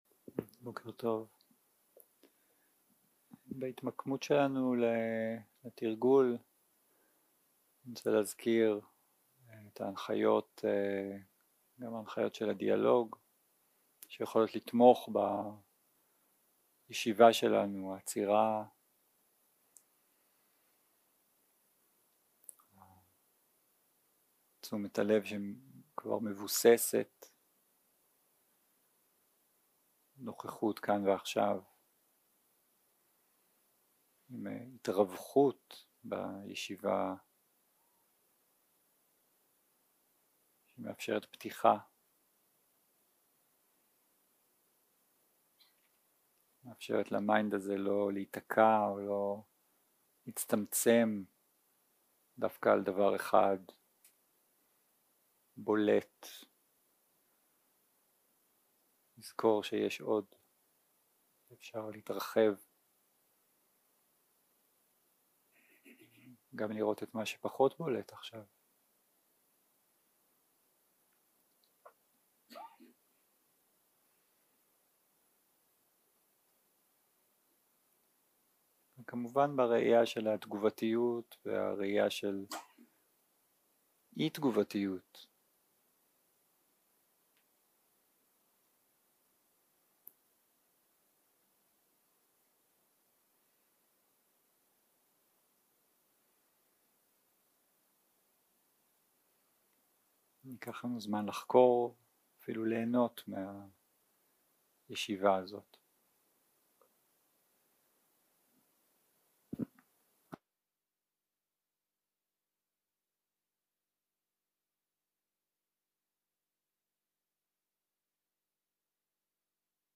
ום 4 - הקלטה 7 - בוקר - הנחיות למדיטציה - מדיטציה ותרגול דיאלוג תובנה - הקשבה בדיאלוג 0:00 0:00 סוג ההקלטה: Dharma type: Guided meditation שפת ההקלטה: Dharma talk language: Hebrew